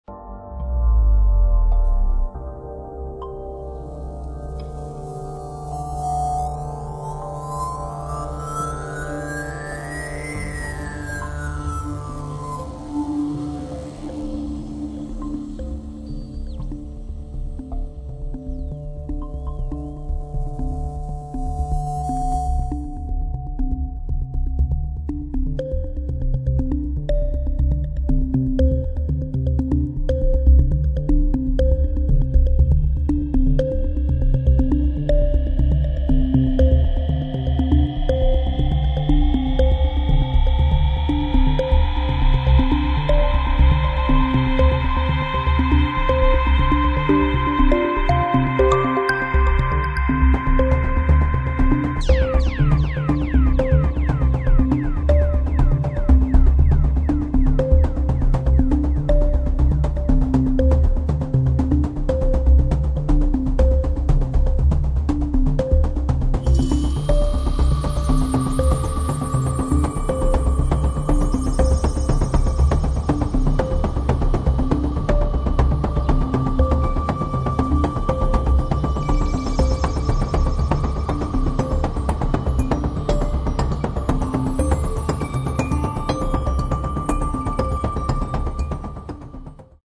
Ambient Version